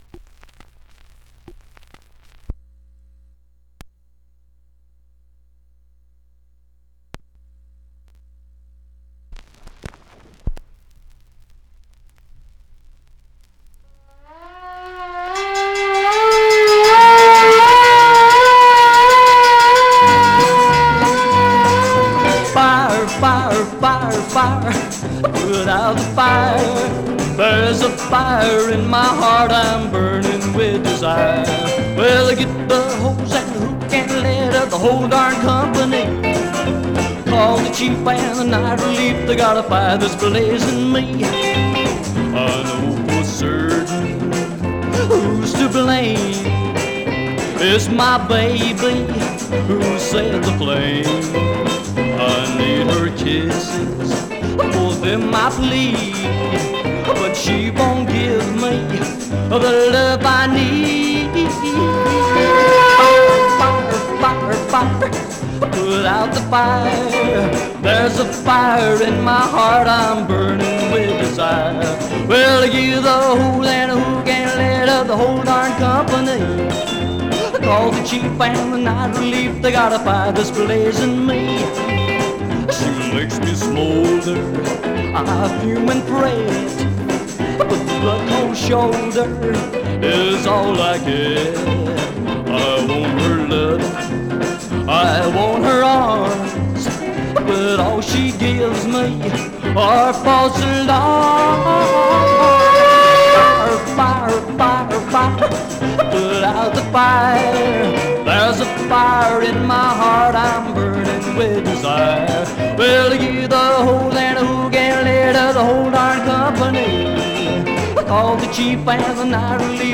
Stereo/mono Mono
Rockabilly